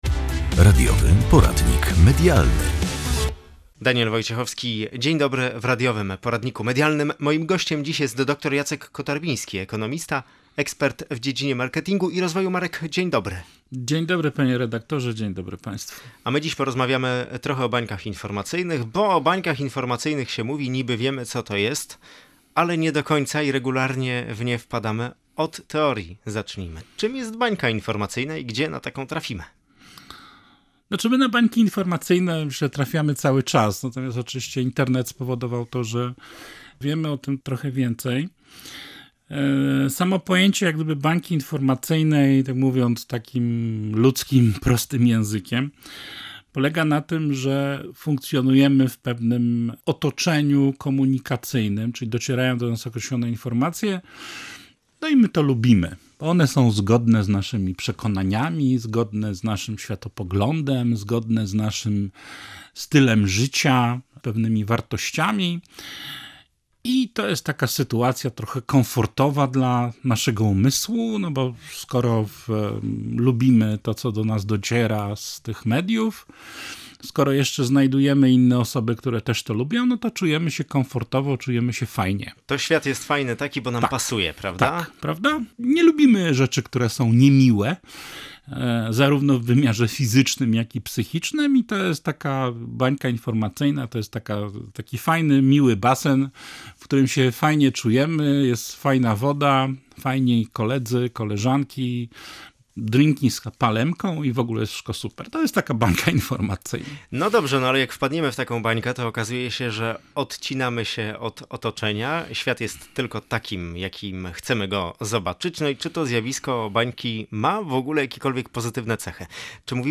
Zapytaliśmy eksperta